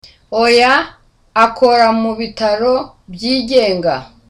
Dialogue
(Gladly)